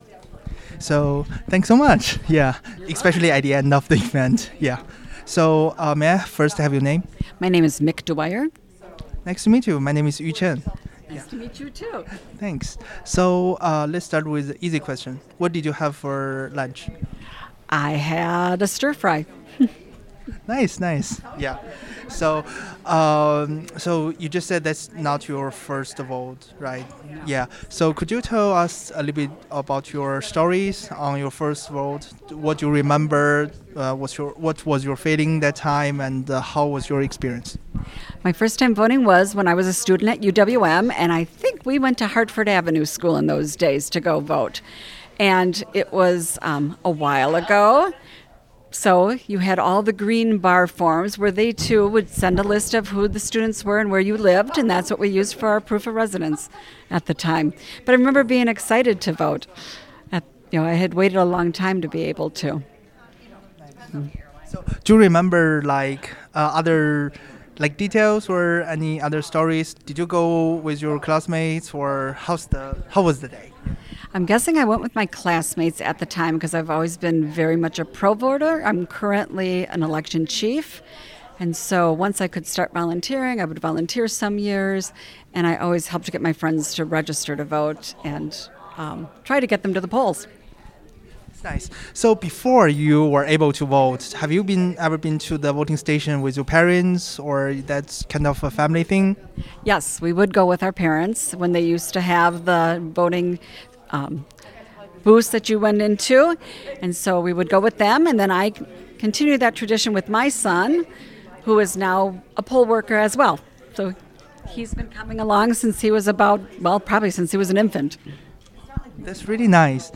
Location Milwaukee Central Library